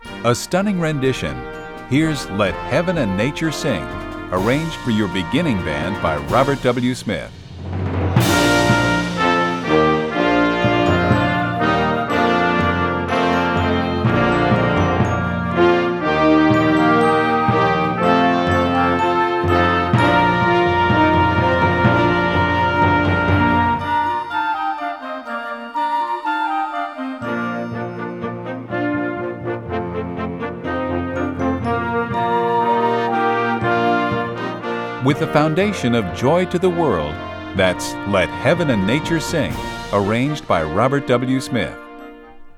Besetzung: Blasorchester
An opening fanfare or a superior holiday concert closer.